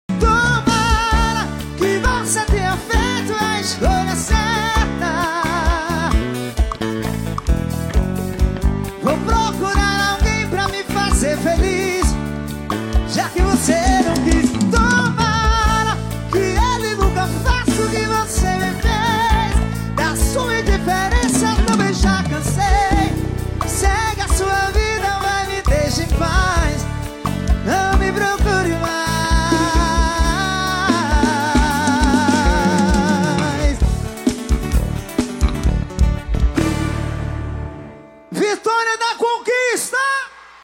Festival de Inverno Bahia 2025 no ritmo da sofrência